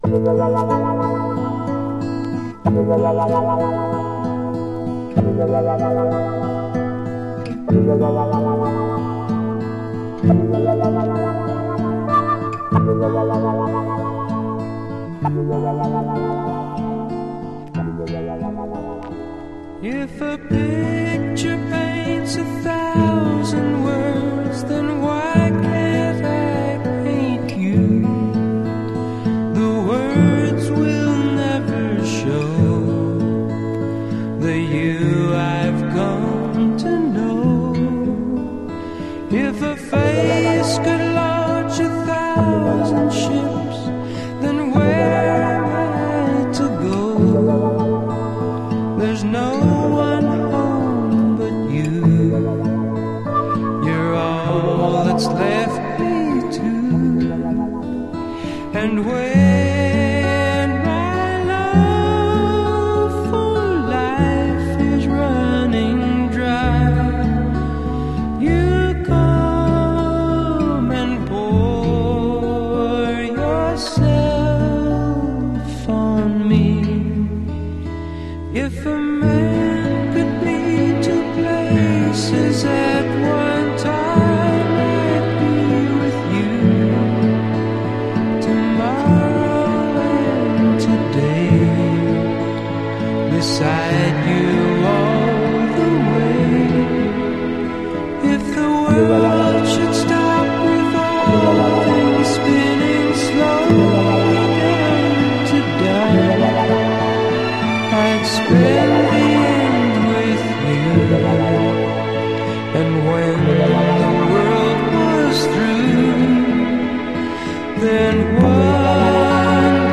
Genre: Soft Rock